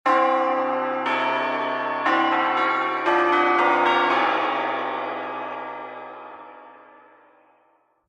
Scary Chimes Sound Button - Free Download & Play
Sound Effects Soundboard264 views